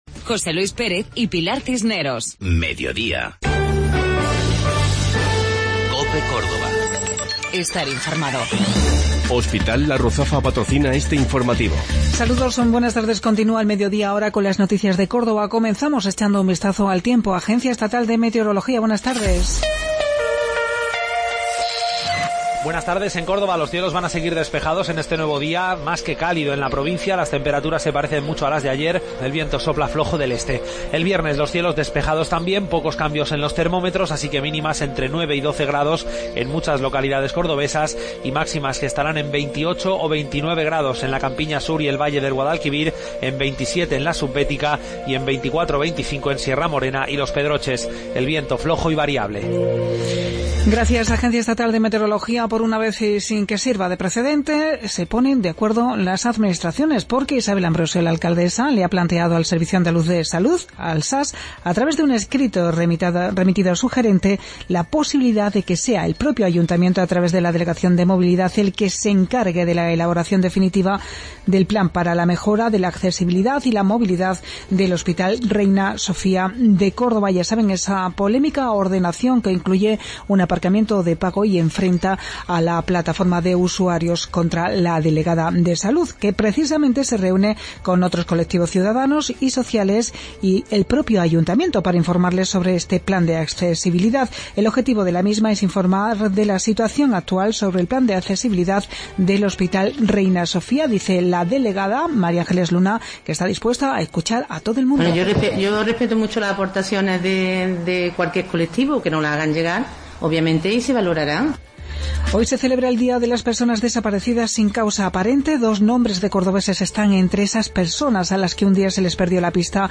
Mediodía en Cope. Informativo local 9 de Marzo 2017